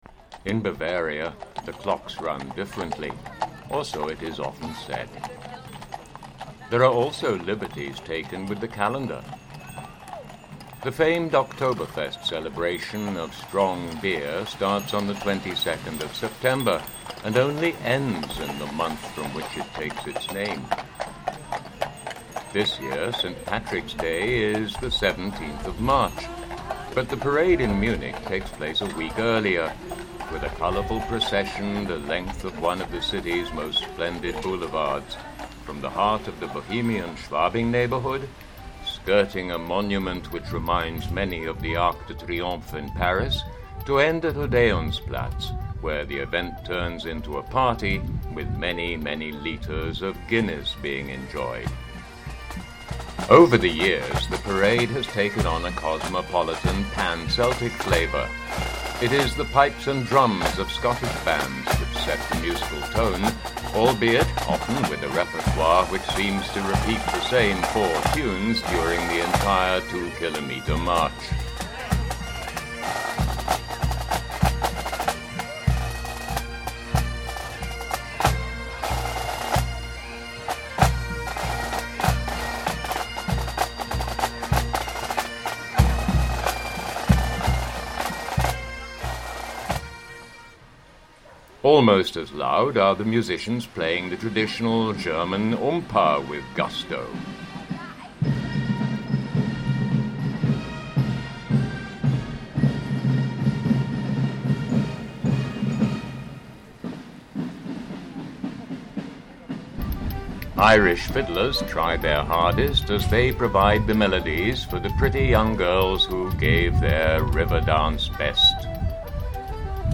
St. Patricks Day Parade - Munich - 2012
Experiment using MixPad audio software